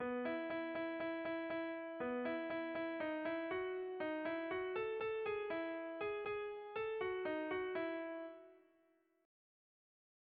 Erromantzea
Araotz < Oñati < Debagoiena < Gipuzkoa < Euskal Herria
A-B